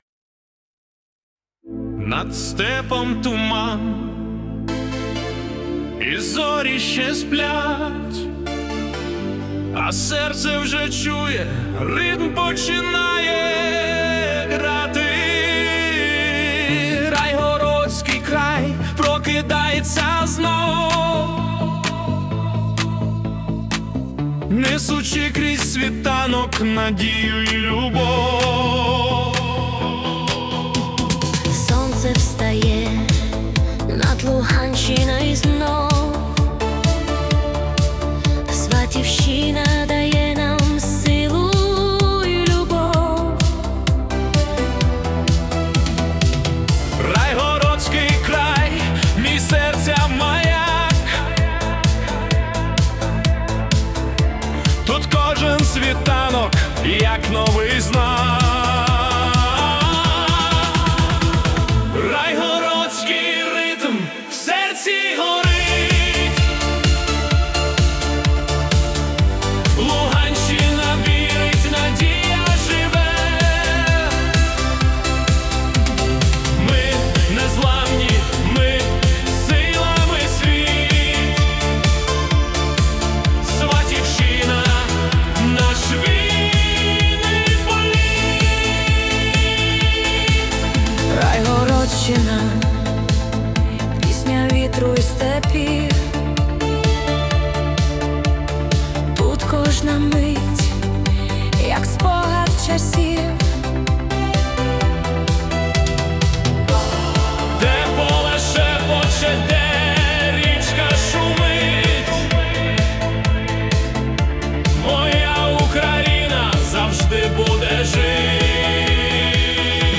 🎵 Жанр: Italo Disco (130 BPM)
яка захоплює з перших нот пульсуючого басу та веде за собою.
Поєднання електронного драйву та глибокого змісту